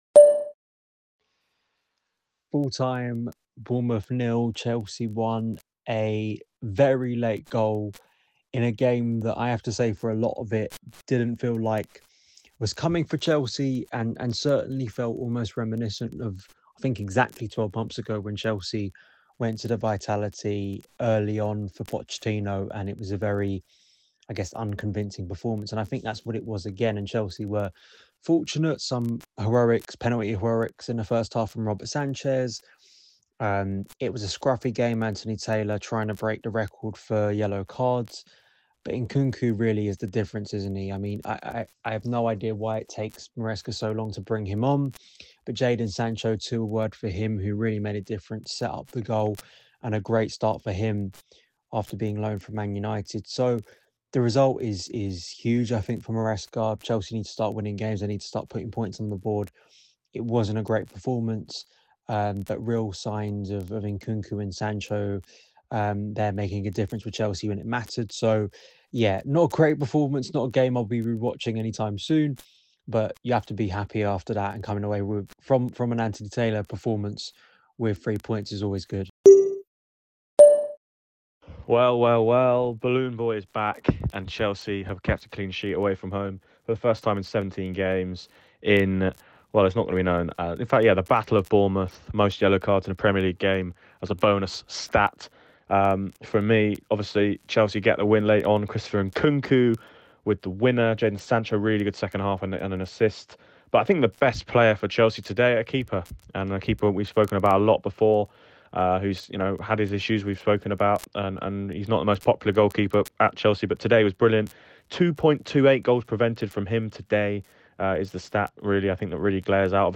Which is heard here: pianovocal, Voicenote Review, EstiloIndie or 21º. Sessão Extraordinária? Voicenote Review